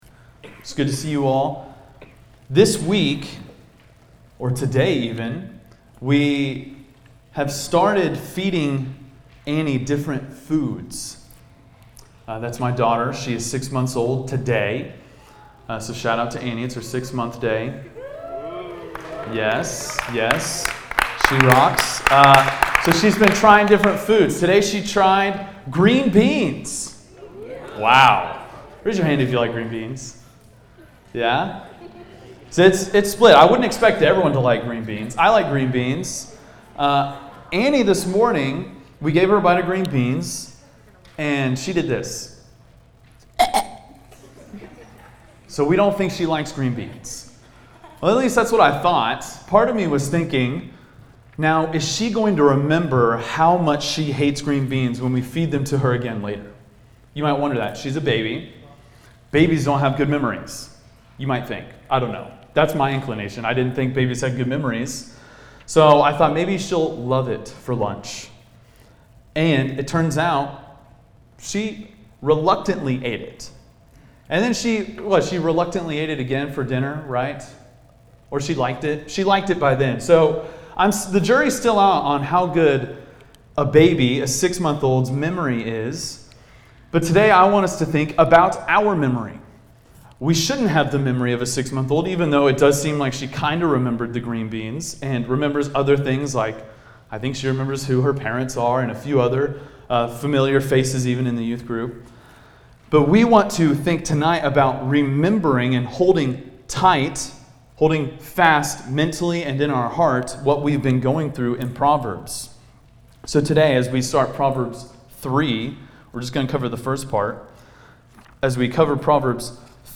preaches through Proverbs 3:1-12.